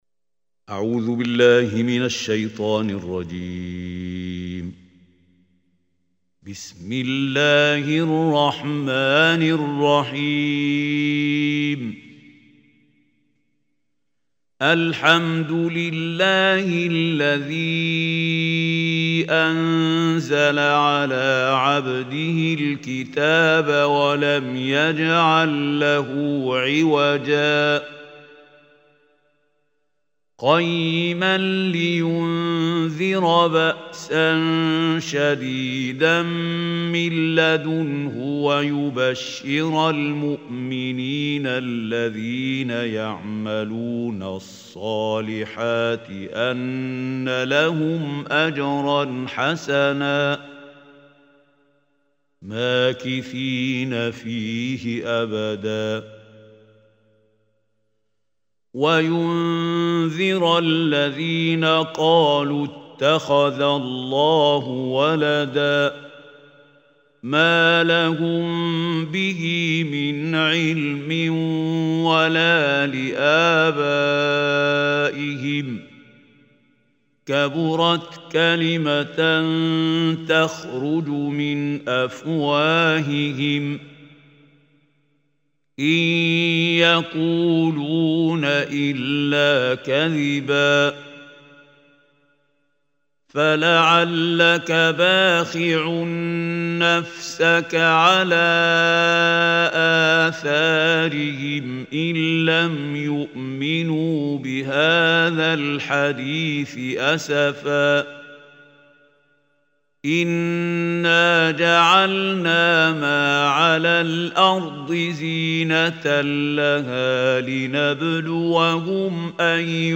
Surah Kahf Recitation by Mahmoud Khalil Al Hussary
Surah al Kahf is 18th chapter / Surah of Holy Quran. Listen online and download beautiful Quran tilawat / recitation of Surah Al Kahf in the beautiful voice of Qari Mahmoud Khalil Al Hussary.